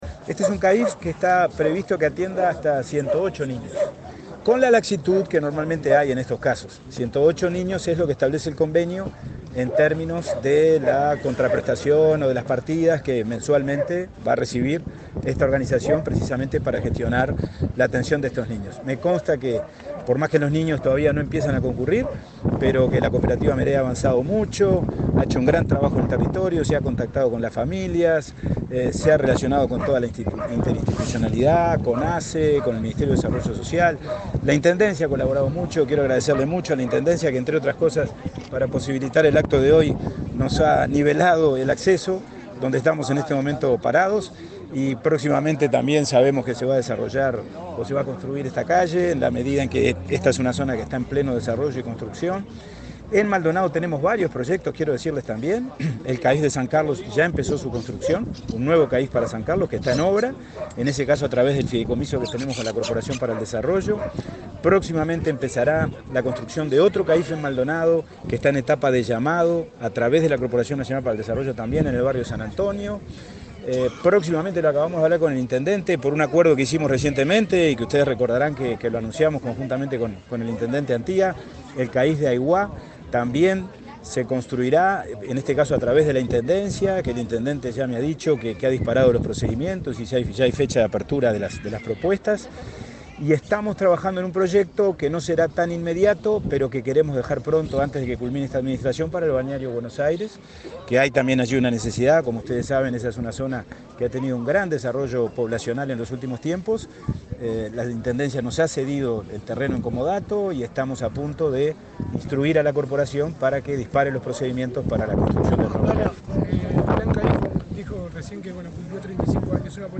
Declaraciones del presidente del INAU, Pablo Abdala
Declaraciones del presidente del INAU, Pablo Abdala 12/09/2023 Compartir Facebook X Copiar enlace WhatsApp LinkedIn El presidente del Instituto del Niño y el Adolescente del Uruguay (INAU), Pablo Abdala, dialogó con la prensa en Maldonado, antes de participar en el acto de entrega de la obra del nuevo centro de atención a la infancia y la familia (CAIF) Los Caracoles.